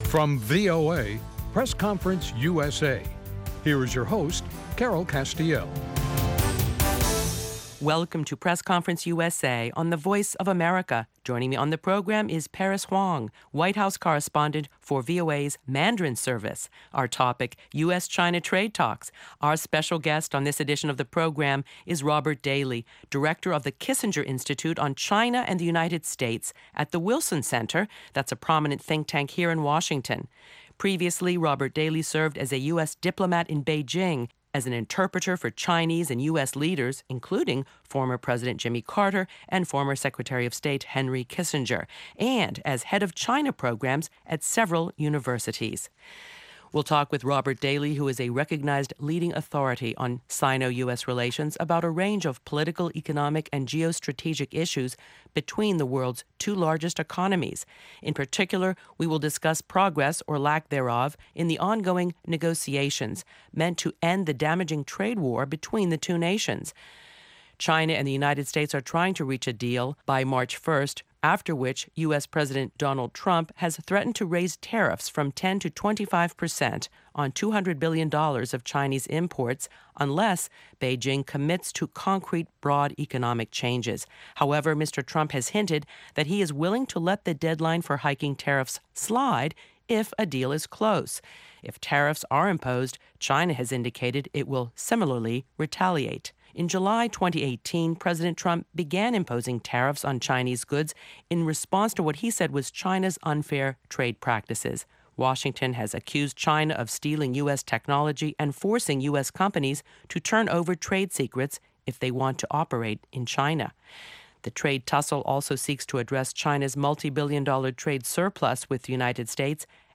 via SKYPE, about the politics and substance of ongoing trade talks.